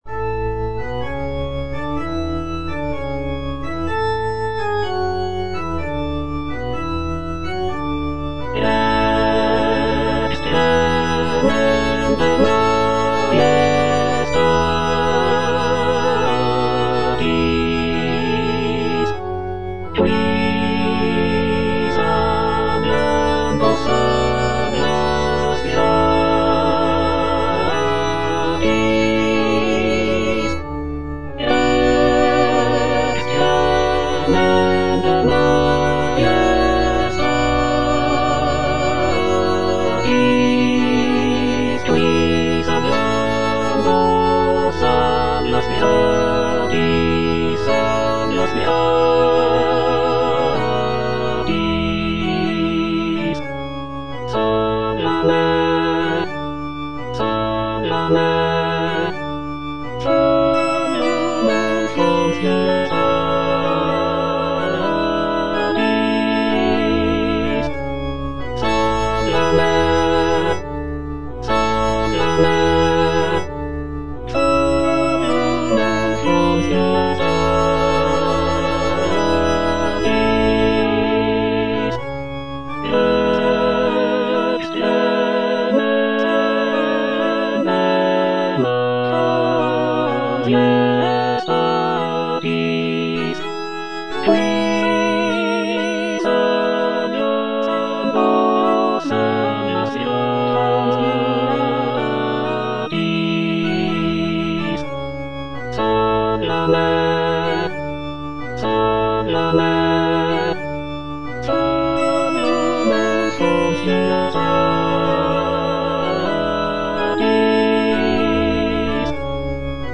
(All voices)